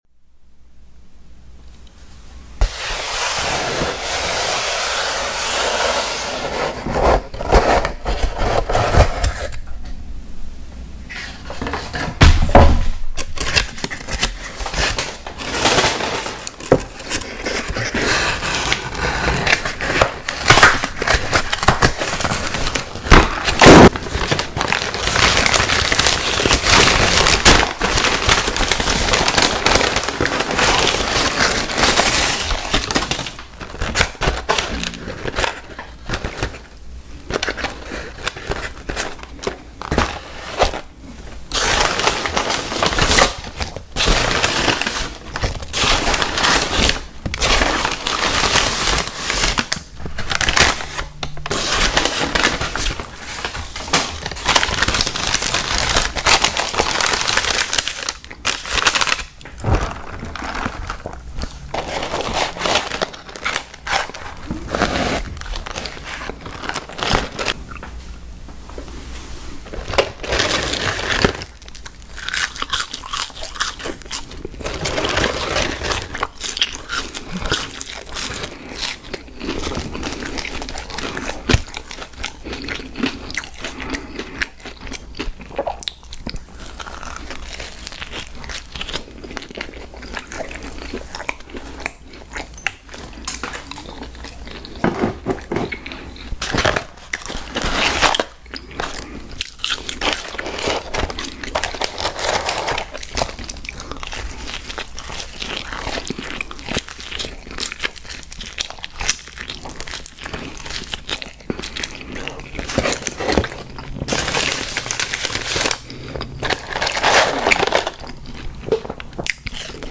ASMR DOMINO'S PIZZA AND WENDY'S sound effects free download
ASMR DOMINO'S PIZZA AND WENDY'S FRIES MUKBANG EATING NO TALKING SOUNDS COMMERCIAL - Part 1